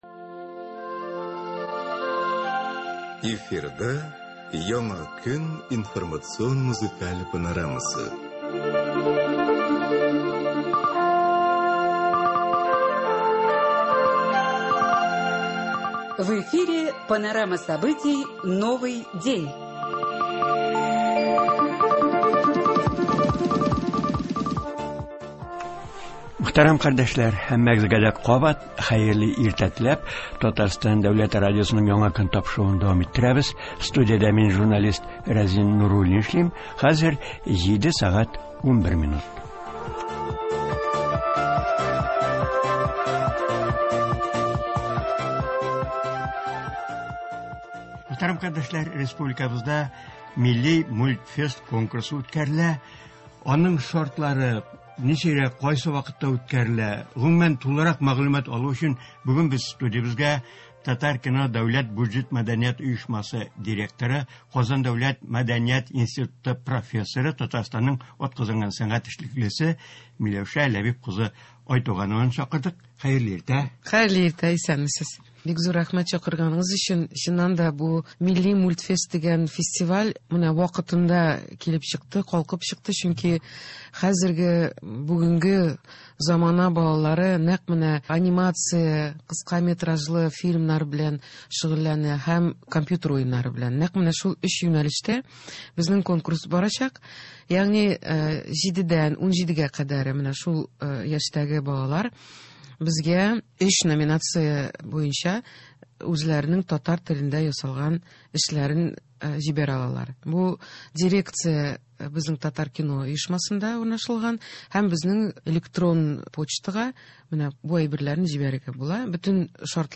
Туры эфир (23.11.20)